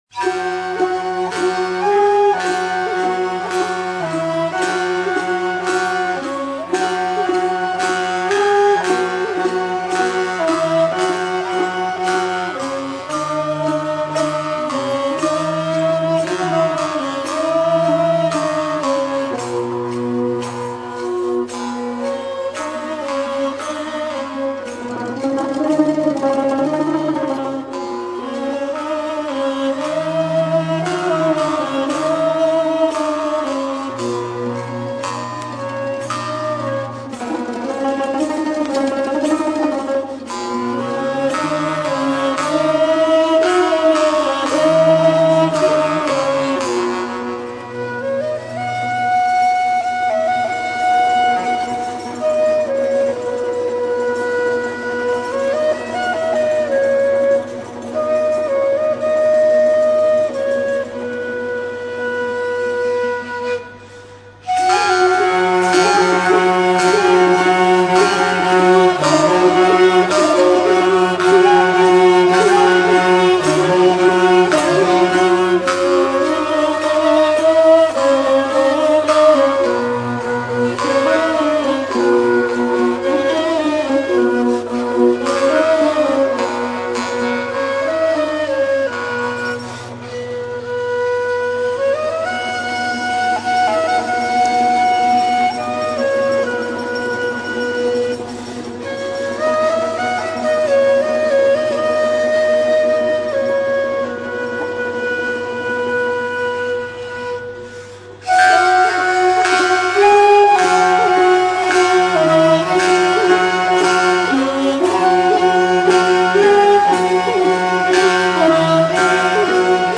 жанр кобызовой музыки
Переложение для ансамбля народных инструментов.